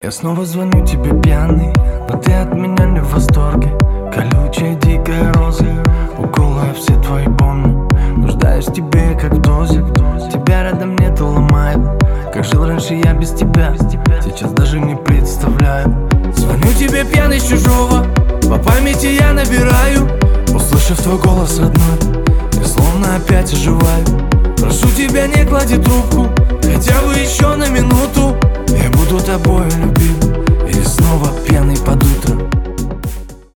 клубные , поп